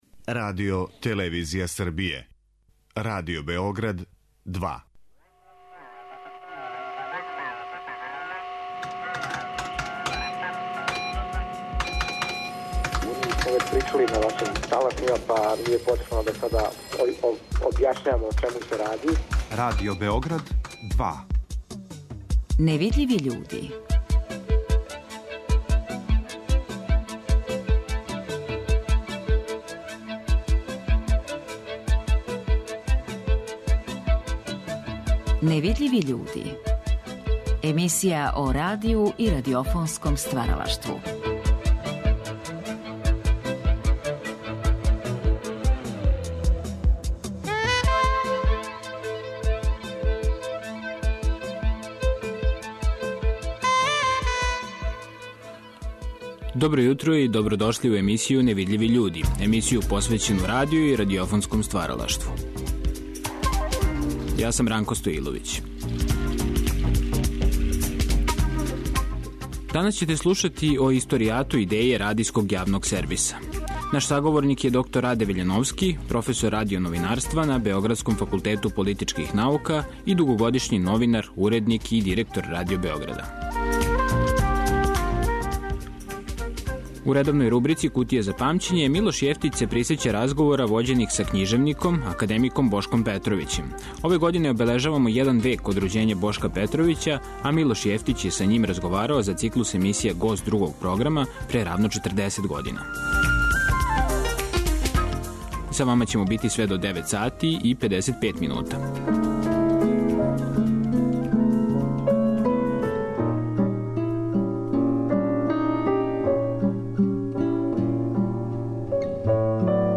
Подсетићемо се у овом издању емисије "Невидљиви људи" гласа Властимира Стојиљковића.